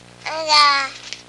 Baby Goo Sound Effect
Download a high-quality baby goo sound effect.
baby-goo-1.mp3